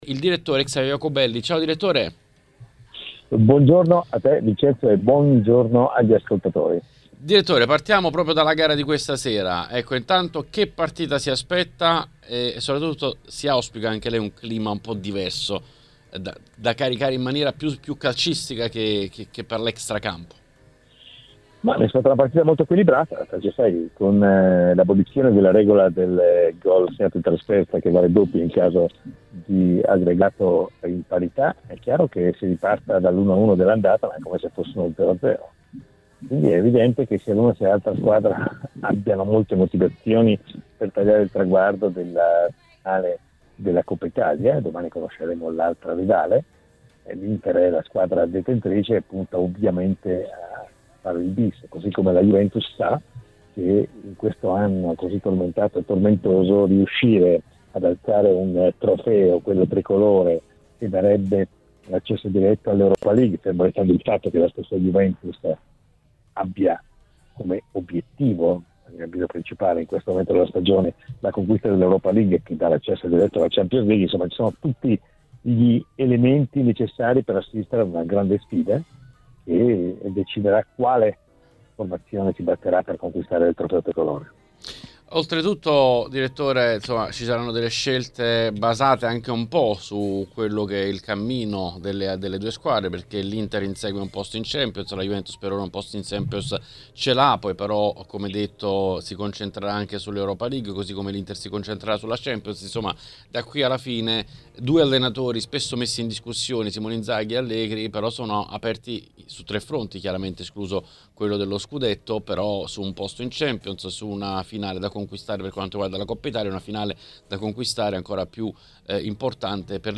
Durante l’appuntamento odierno con L’Editoriale è intervenuto sulle frequenze di TMW Radio Xavier Jacobelli.